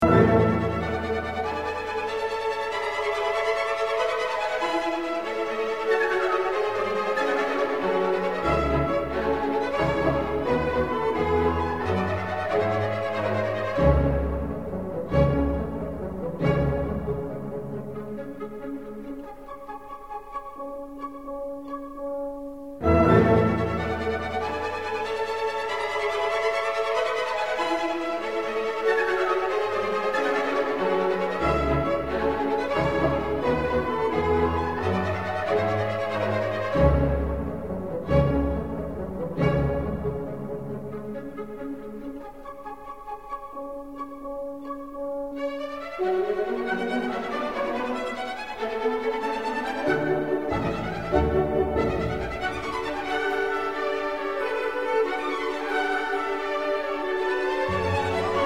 Classiquement en 4 mouvements, la Symphonie de Stephanescu, résolument optimiste, respire une certaine joie de vivre et utilise déjà des éléments folkloriques roumains dans son finale.